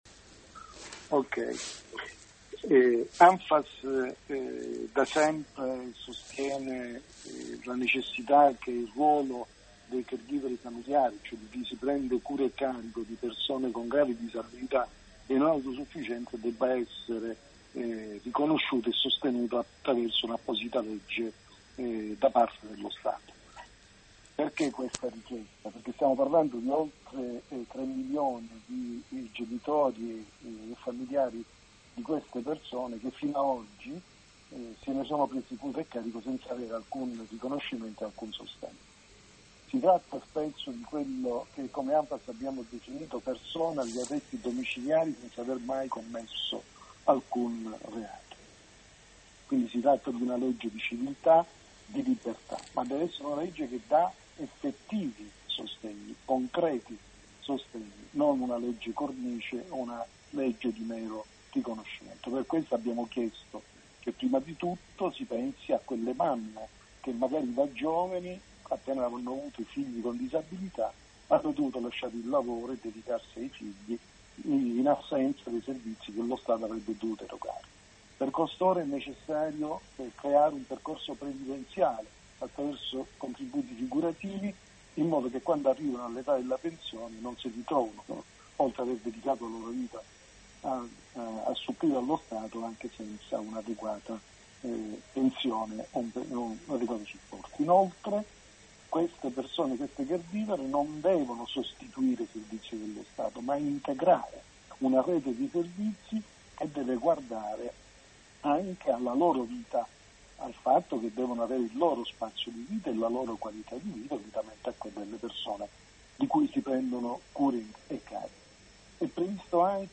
Ecco il suo intervento ai nostri microfoni.